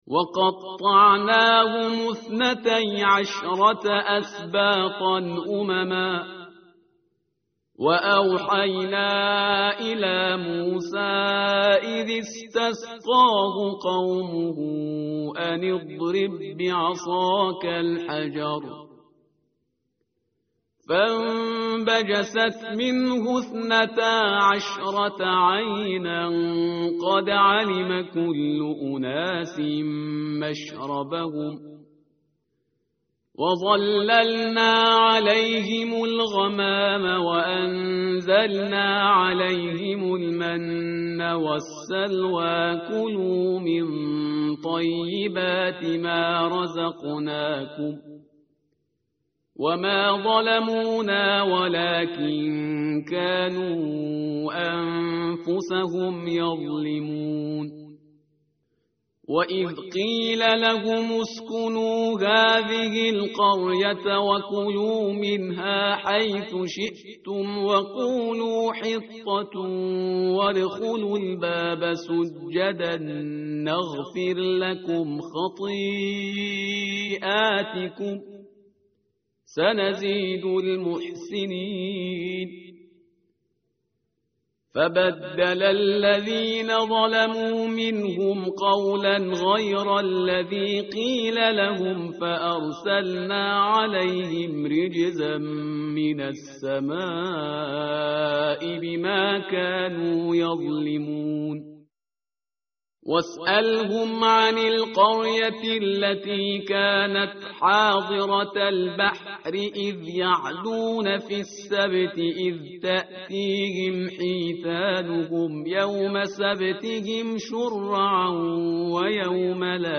tartil_parhizgar_page_171.mp3